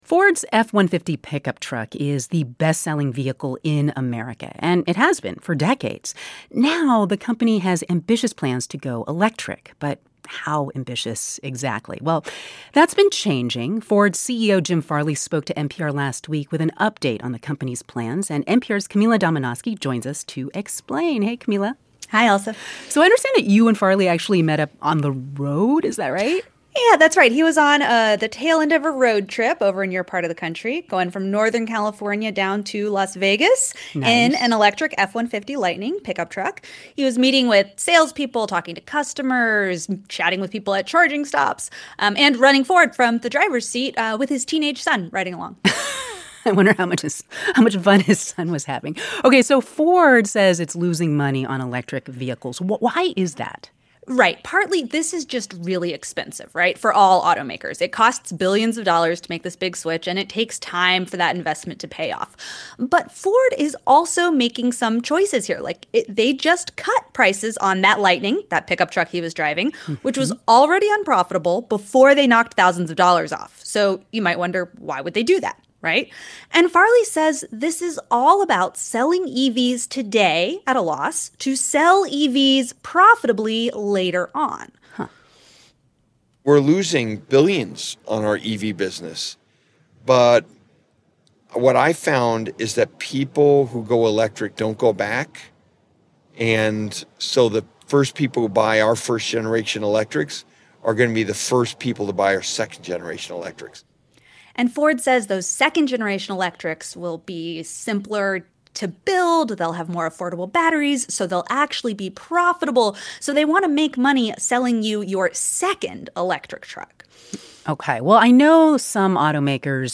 In a wide-ranging interview, Ford's CEO shares his thoughts about his company's ramp-up in electric cars and the state of charging.